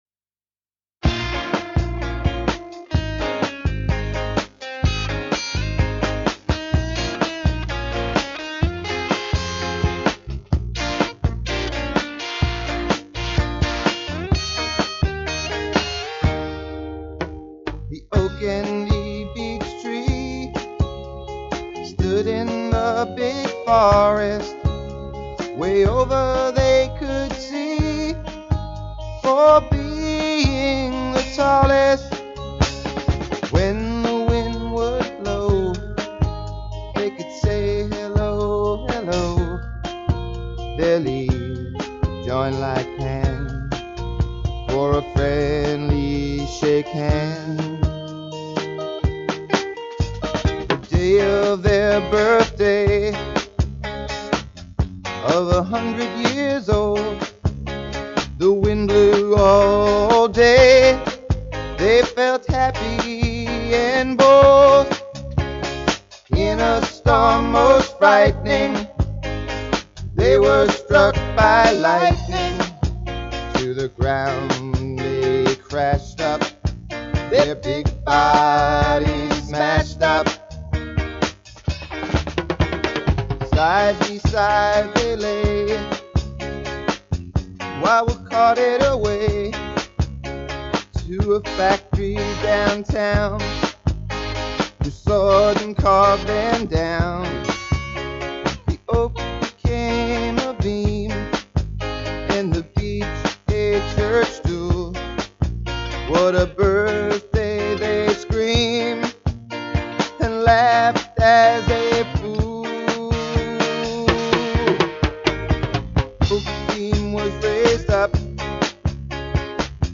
Enregistré à Valotte